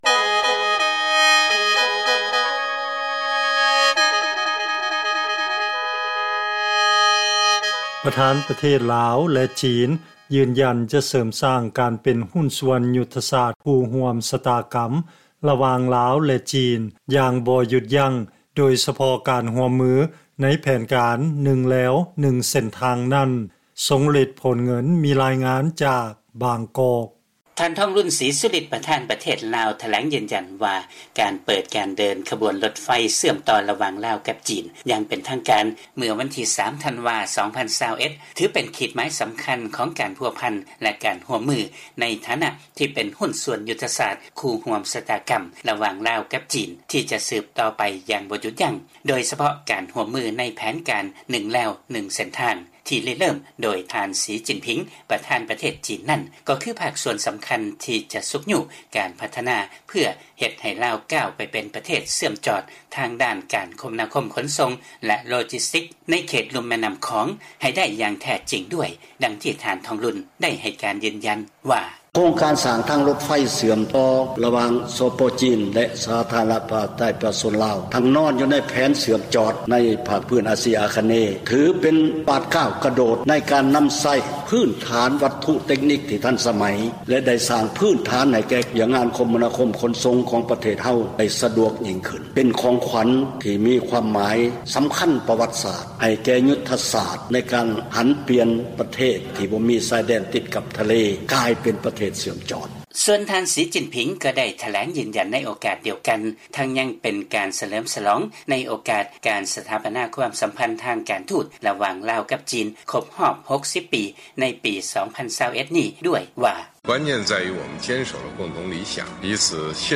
ລາຍງານກ່ຽວກັບ ປະທານປະເທດ ລາວ-ຈີນ ຢືນຢັນຈະເສີມສ້າງການເປັນຫຸ້ນສ່ວນຍຸດທະສາດຄູ່ຮ່ວມຊະຕາກໍາລະຫວ່າງ ລາວ-ຈີນ ຢ່າງບໍ່ຢຸດຢັ້ງ